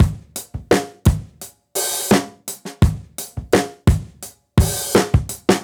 Index of /musicradar/dusty-funk-samples/Beats/85bpm
DF_BeatD_85-03.wav